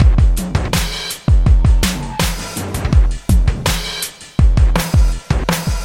描述：在fruitity loops中创建。经过压缩，在Cool Edit中调整了EQ。
Tag: 164 bpm Industrial Loops Drum Loops 1 007.96 KB wav Key : Unknown